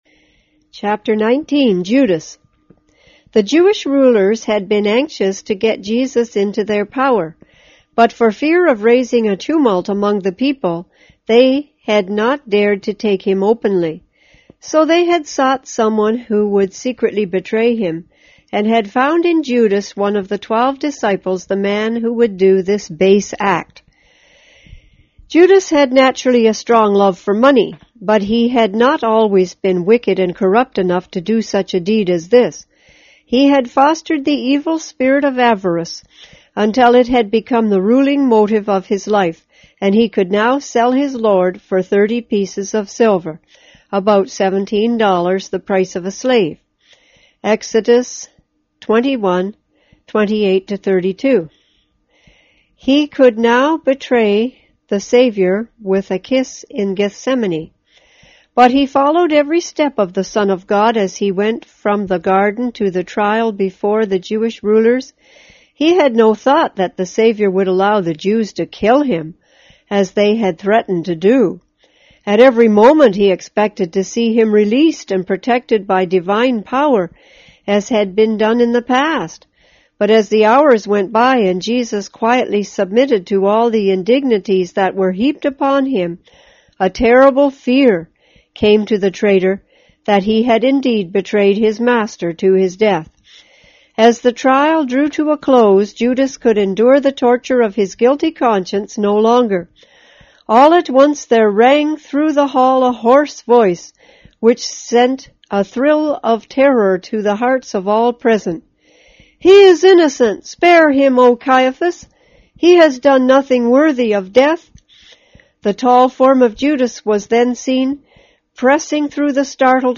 on 2010-06-03 - Ellen G. Whyte Books on Audio